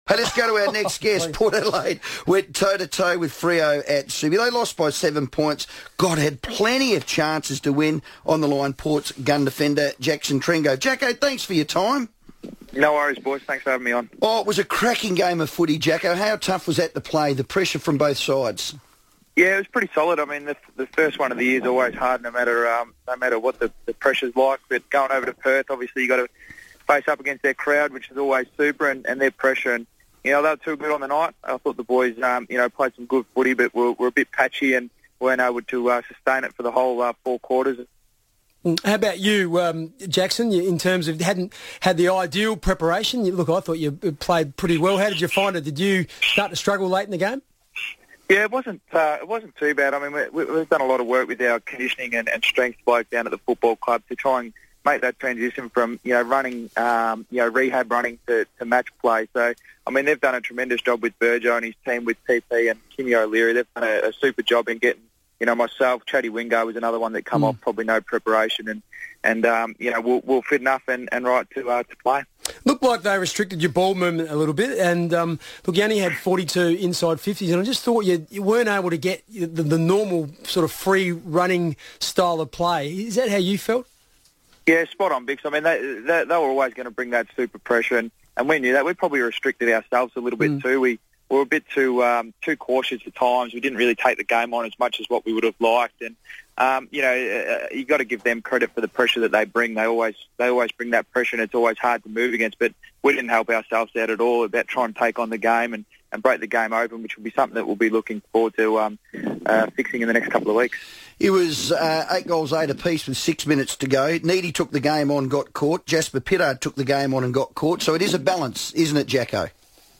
Jackson Trengove FIVEaa interview - Tuesday 7 April, 2015